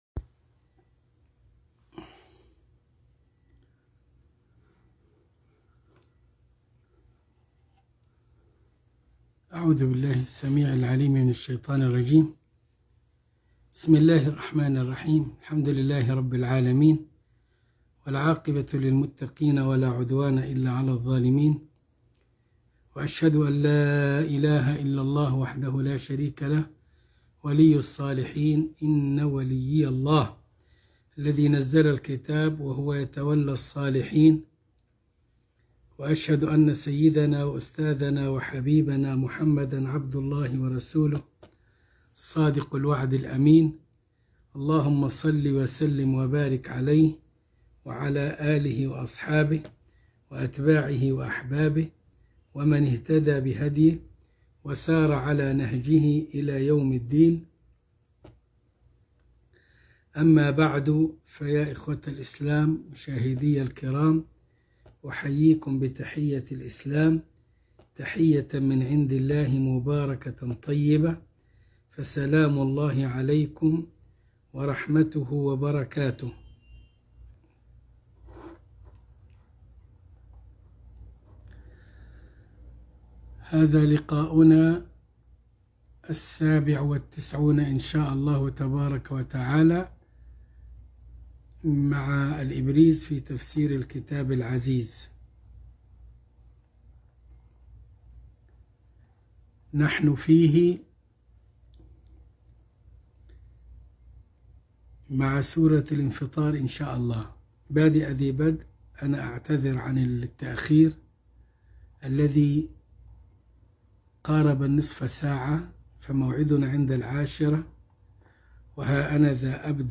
الدرس ٩٨ من الإبريز في تفسير الكتاب العزيز سورة الانفطار